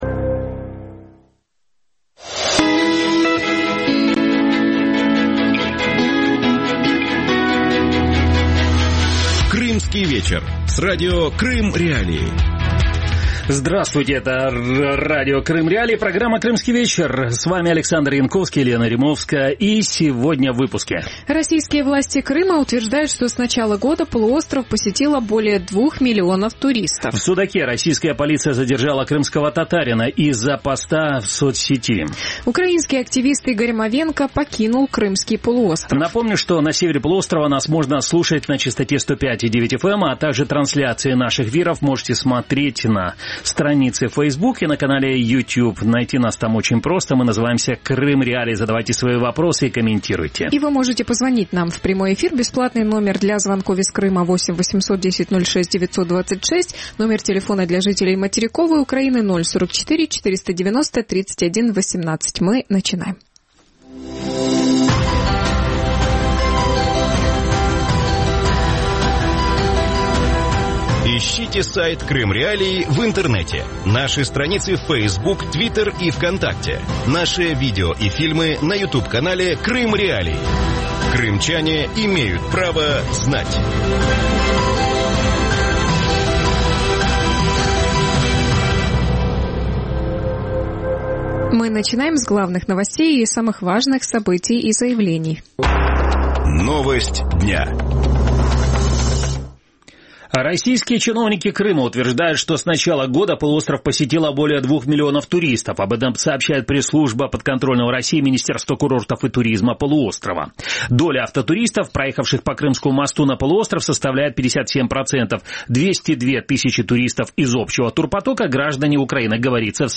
Как открытие Турции для российских силовиков сказалось или скажется на туристическом потоке на полуостров? Гости эфира